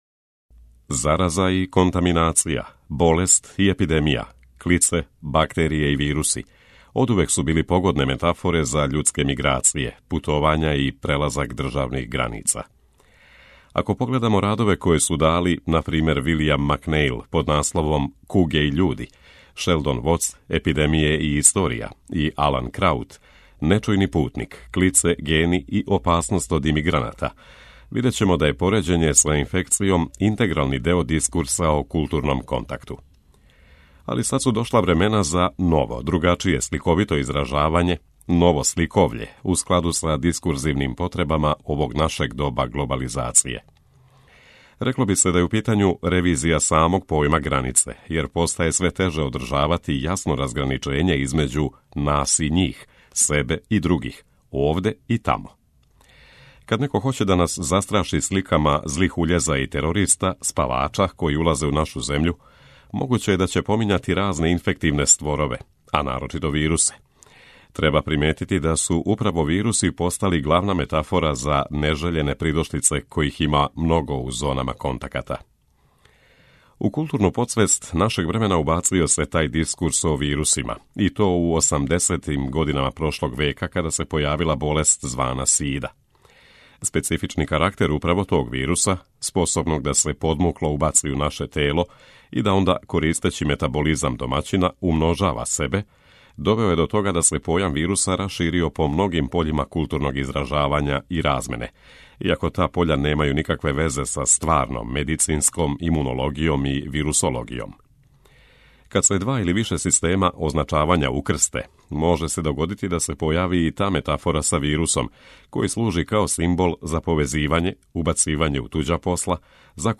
У емисијама РЕФЛЕКСИЈЕ читамо есеје или научне чланке домаћих и страних аутора.